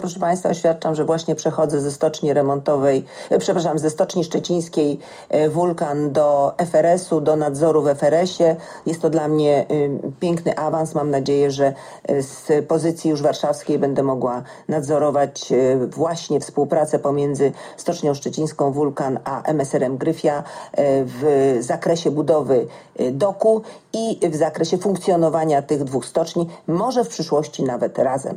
W audycji „Rozmowy pod krawatem” na antenie Radia Szczecin Małgorzata Jacyna-Witt sama poinformowała słuchaczy o swoim awansie.
oswiadczenie-1.mp3